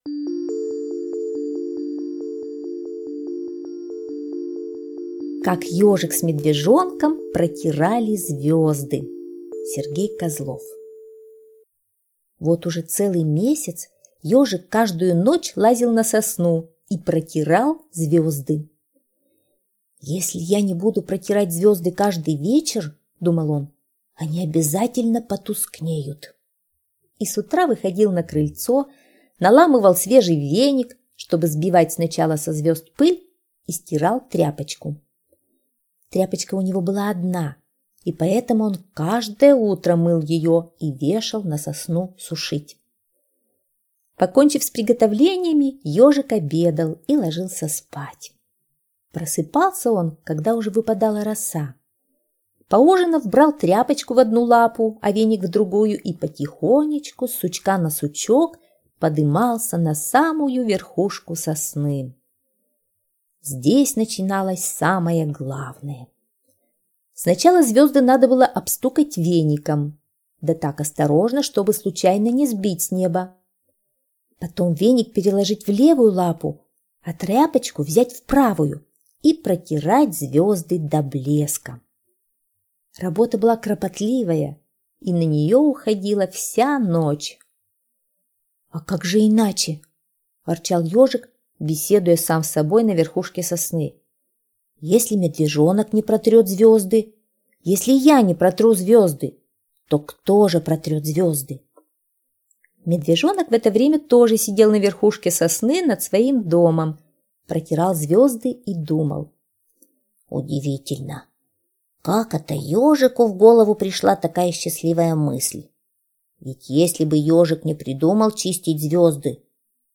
Слушайте Как Ёжик с Медвежонком протирали звезды - аудиосказка Козлова С.Г. Сказка про то, как Ежик с Медвежонком каждый вечер начищали звезды.